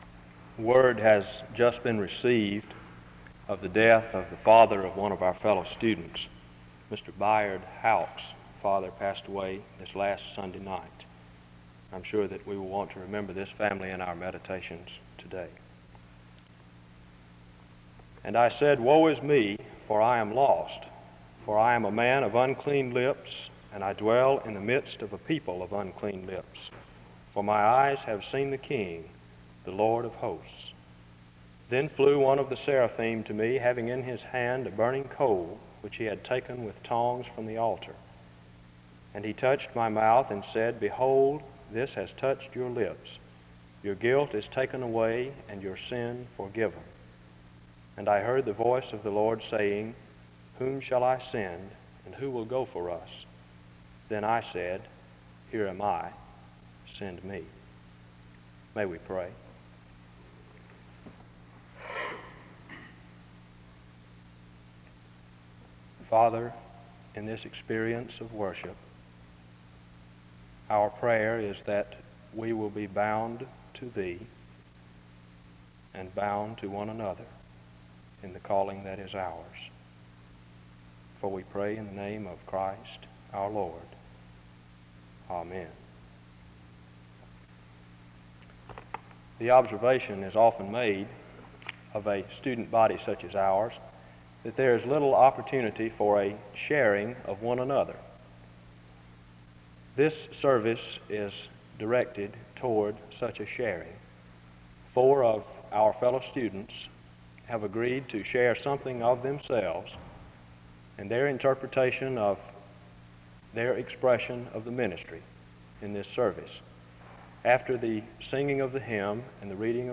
The service begins with announcements and prayer from 0:00-1:26. An introduction is given from 1:29-2:04. Ephesians 3 is read from 2:09-4:58.
Four students share stories of the importance of missions, evangelism, ministry, and churches.
This service was organized by the Student Coordinating Council.